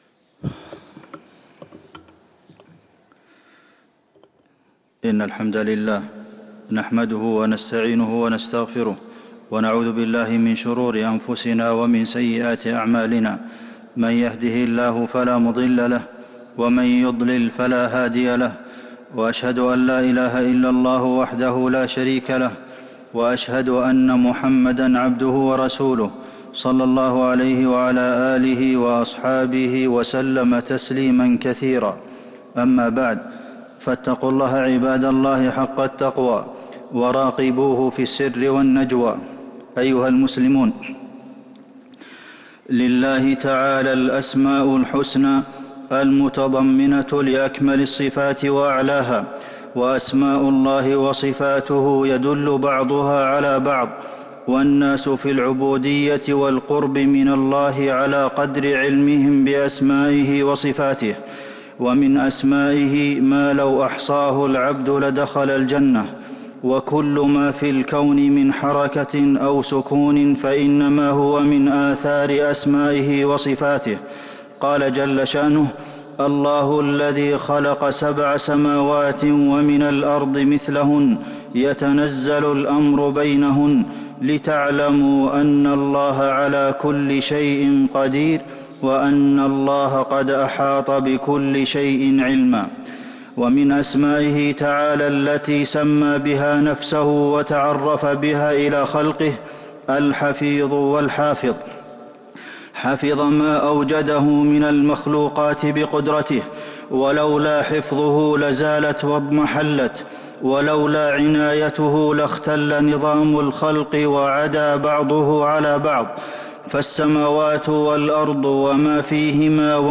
خطبة الجمعة 1-8-1443هـ | khutbat aljumuea 4-3-2022 > خطب الحرم النبوي عام 1443 🕌 > خطب الحرم النبوي 🕌 > المزيد - تلاوات الحرمين